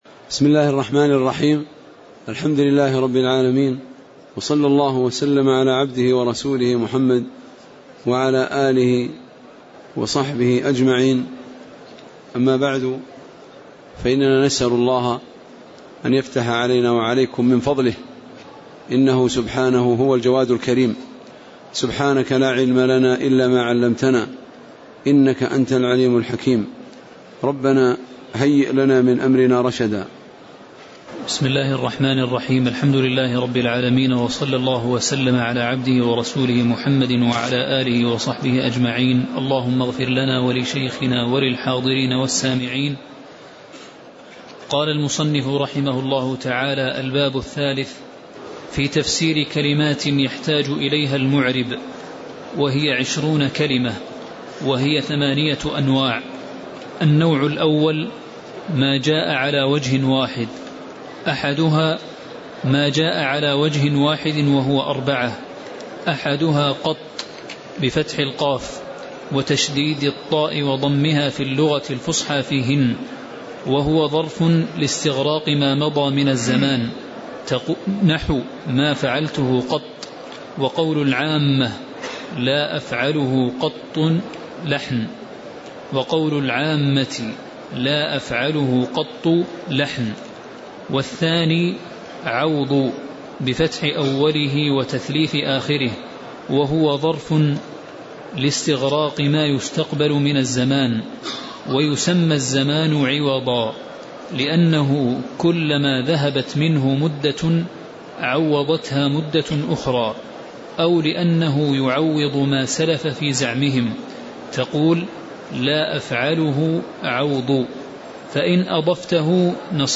تاريخ النشر ٨ شوال ١٤٣٨ هـ المكان: المسجد النبوي الشيخ